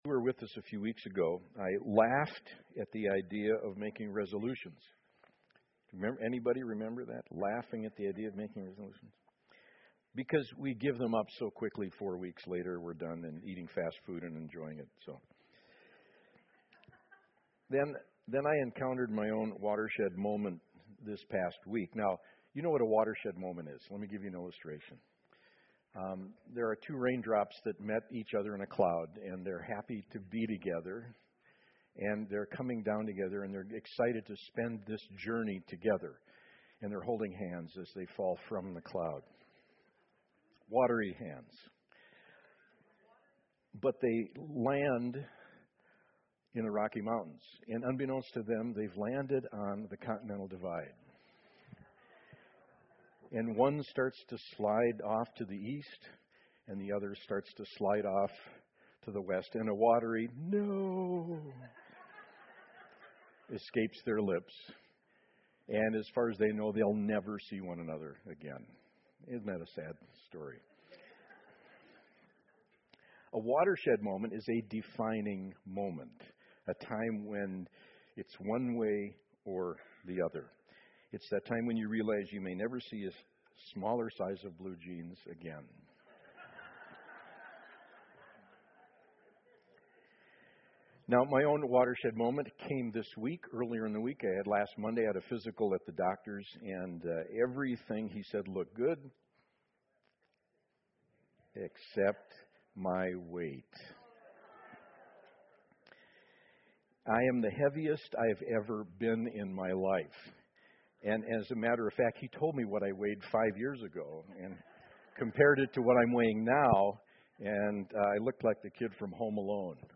SERMONS Grace & Gravity in Repentance The Great Launch Continuing Jesus' Mission January 26